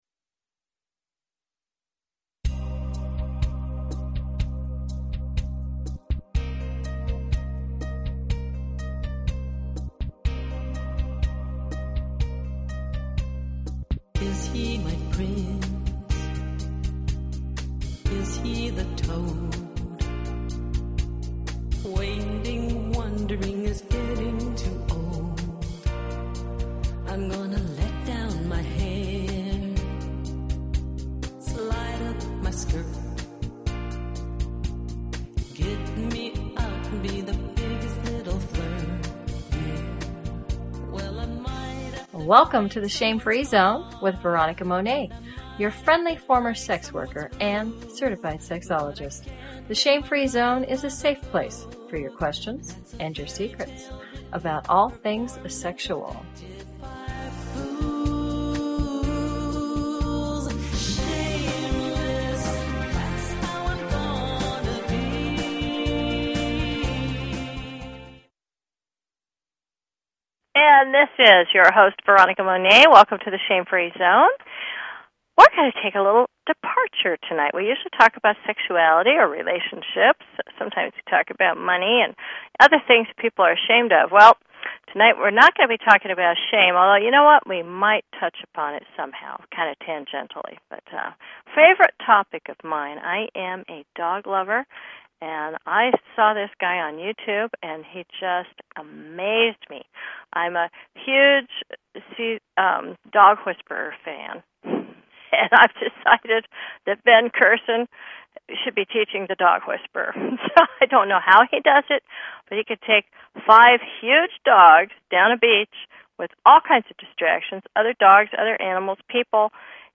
Talk Show Episode, Audio Podcast, The_Shame_Free_Zone and Courtesy of BBS Radio on , show guests , about , categorized as